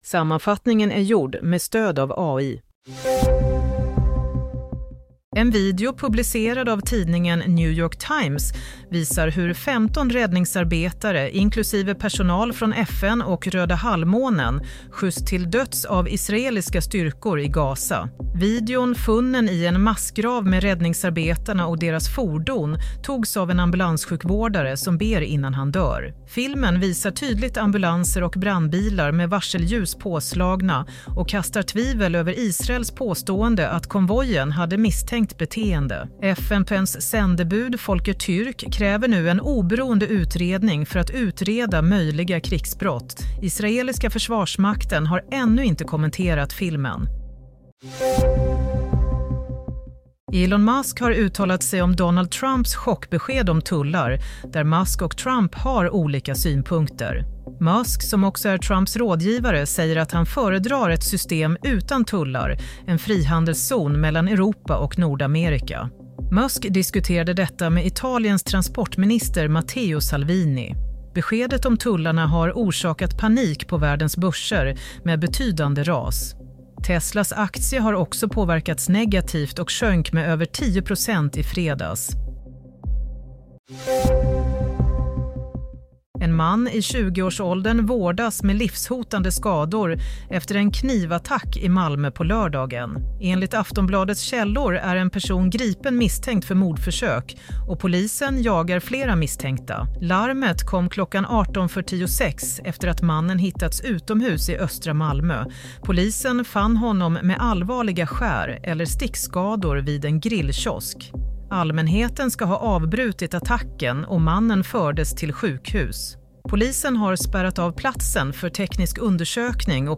Nyhetssammanfattning – 5 april 22:00